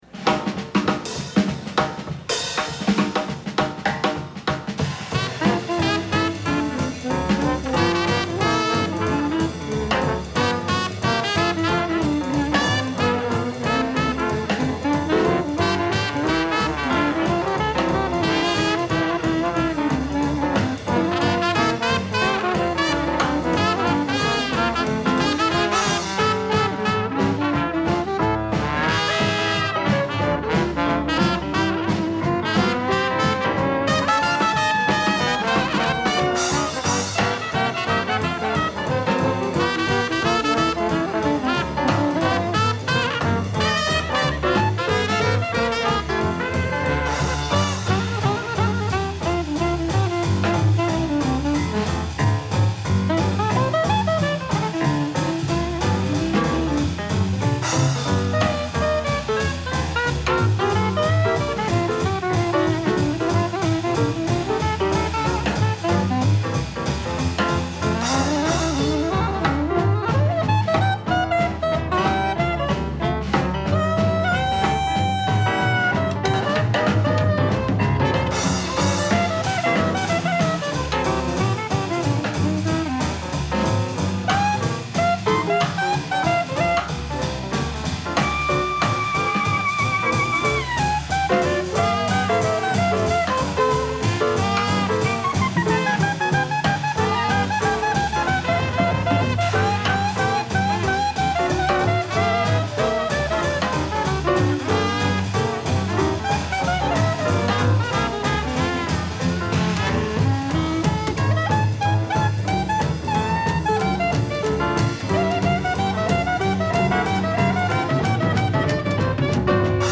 Jazzed Up
Shattuck Hotel, Berkeley CA, May 1985
cornet
trombone
string bass
drums
unissued instrumental